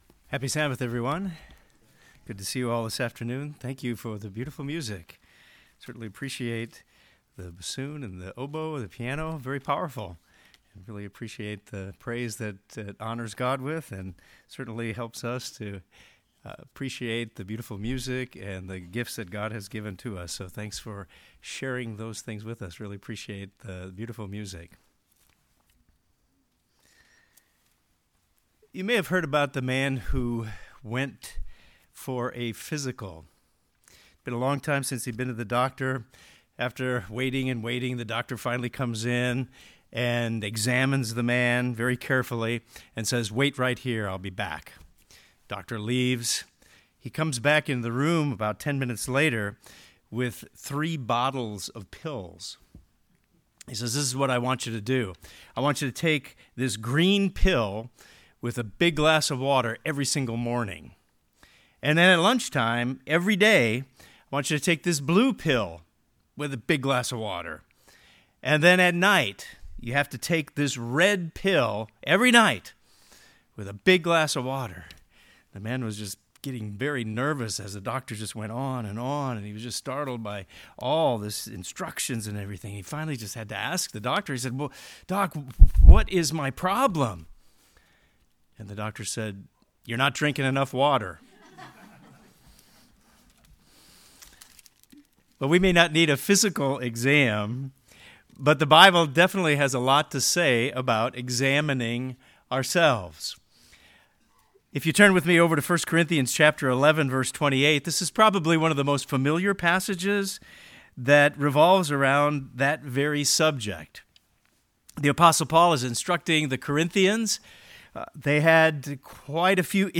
We are to be like a 'dokimazo' and pore over our life to determine if we are a genuine Christian. This sermon discusses what that entails.